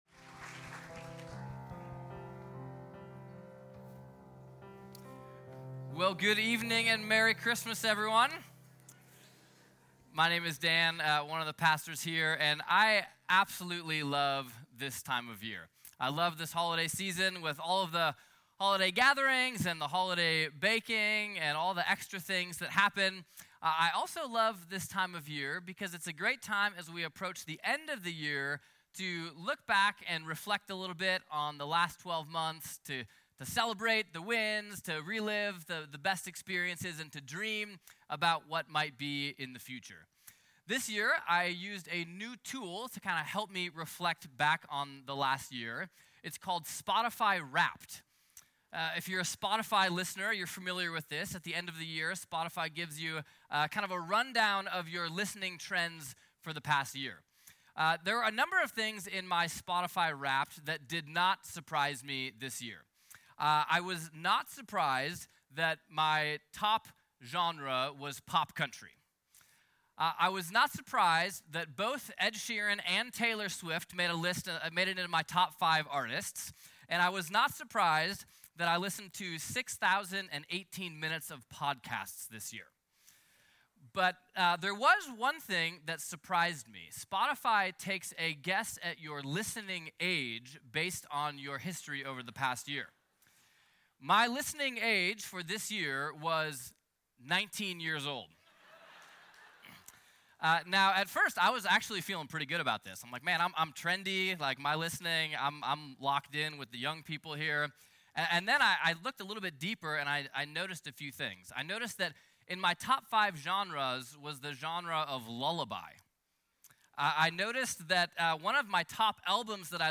A message from the series "Love Came Down."